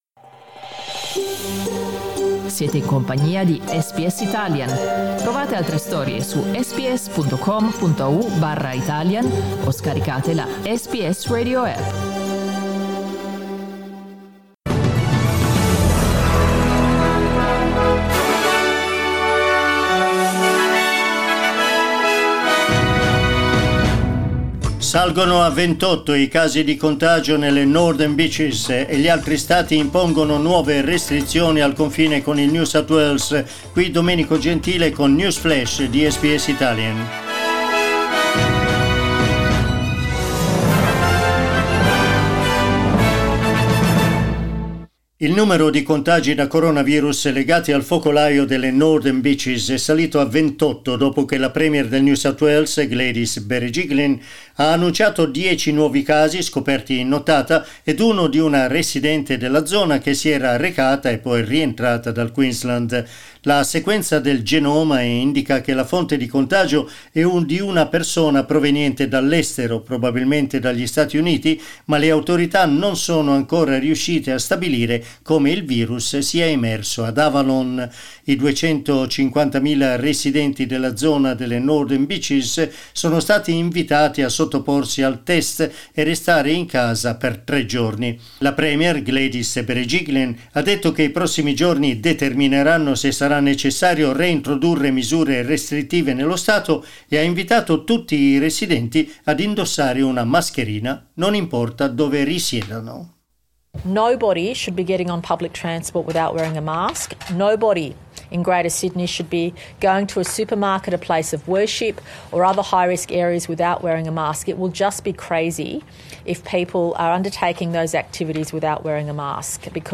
Our news update in Italian.